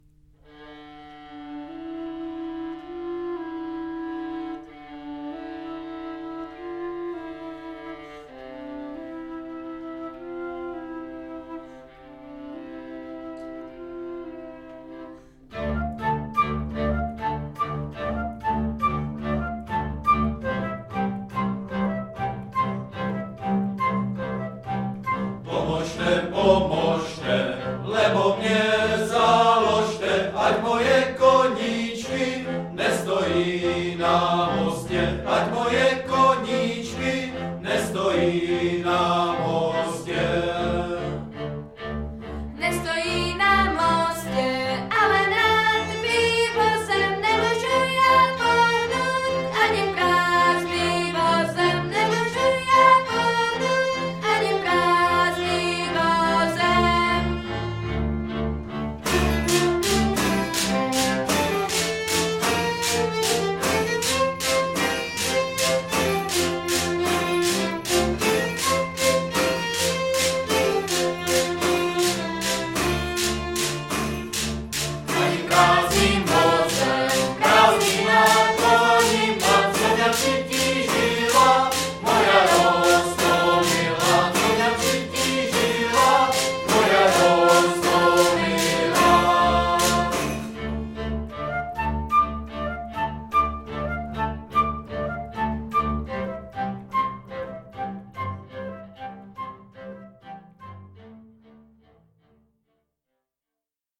Nahrávali jsme ve Spálově v ZUŠce na jaře 2019.